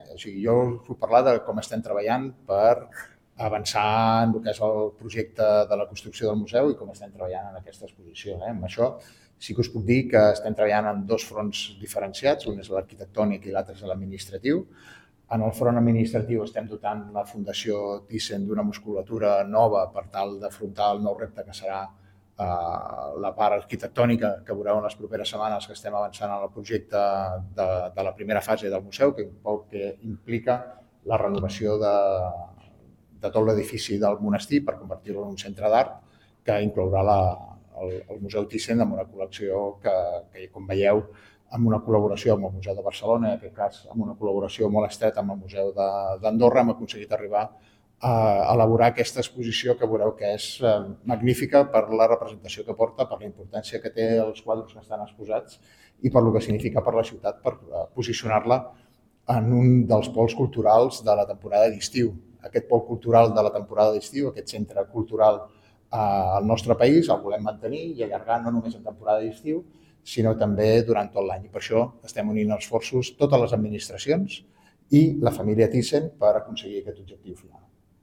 L’alcalde del municipi, Carles Motas, ha explicat que el projecte de creació del Museu Thyssen a Sant Feliu de Guíxols s’està treballant a través de dos àmbits: l’arquitectònic i l’administratiu.